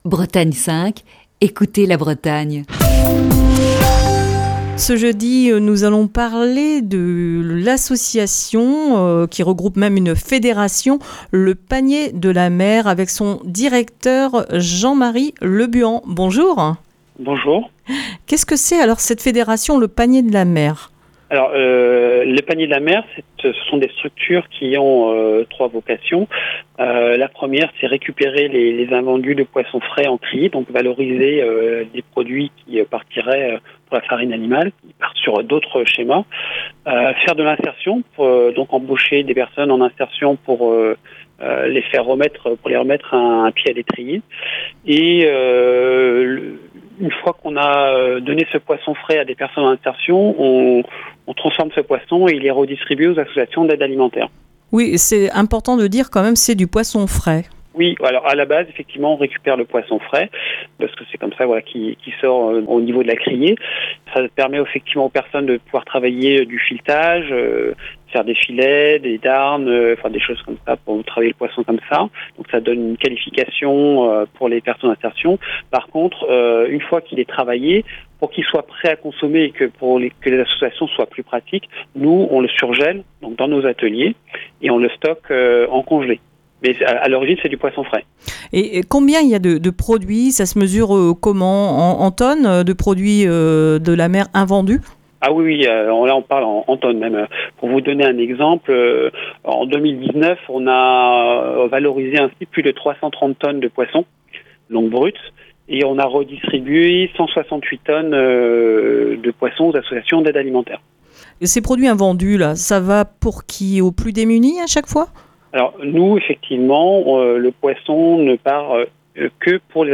Le coup de fil du matin s'accorde une petite parenthèse d'une semaine pour les vacances de la Toussaint.